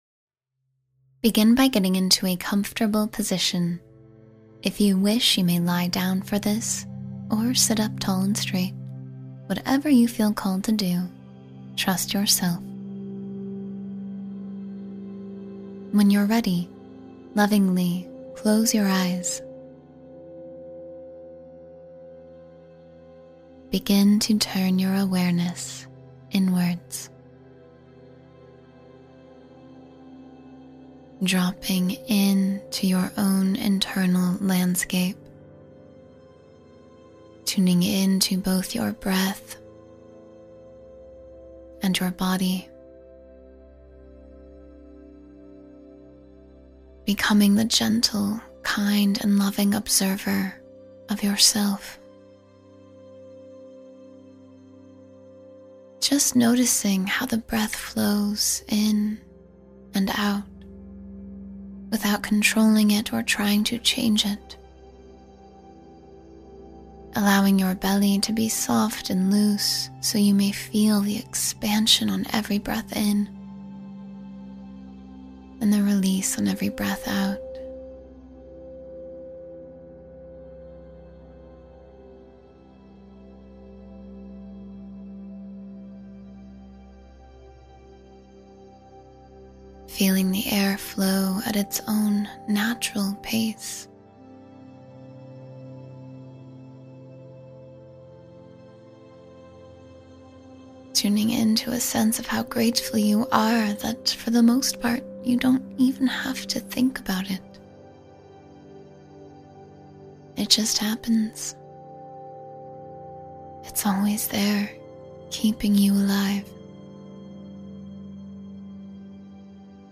Connect with Your Spirit While You Sleep — A Guided Journey to Inner Peace